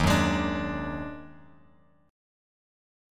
D#mM11 chord